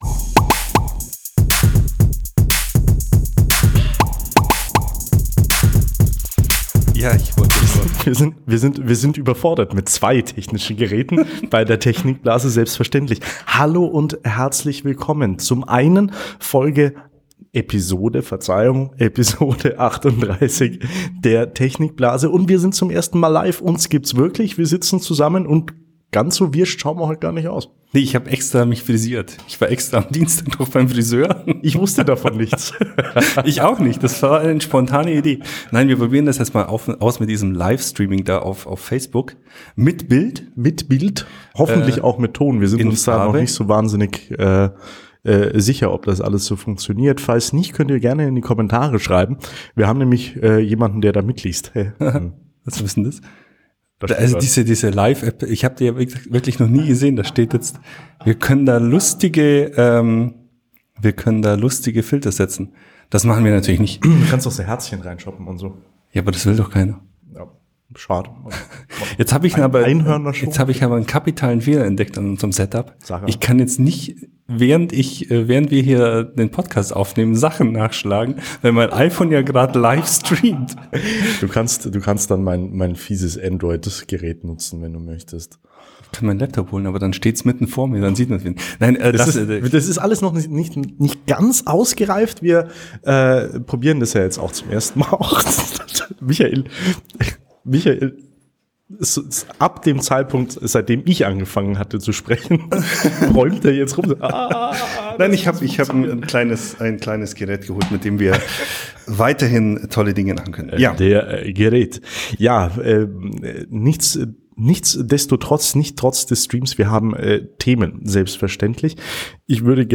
Wir sind live!
Zum ersten Mal haben wir unseren Podcast auch auf Facebook gestreamt.